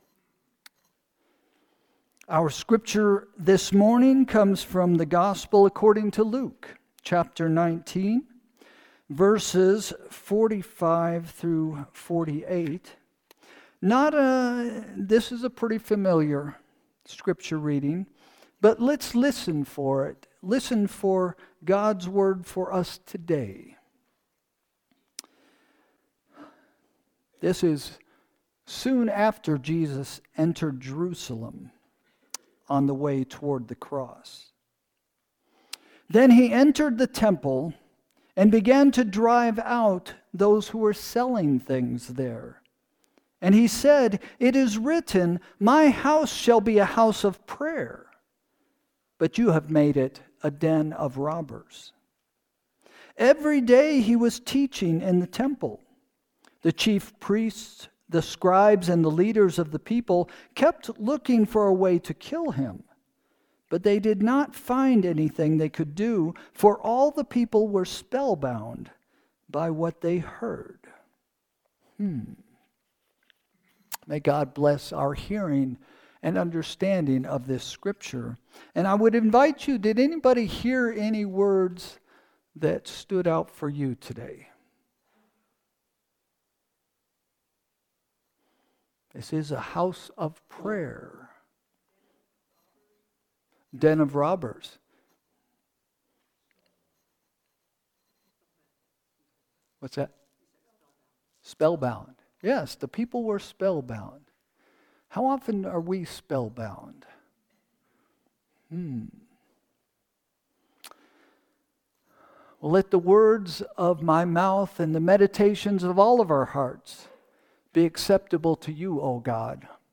Sermon – February 9, 2025 – “Provocative Love” – First Christian Church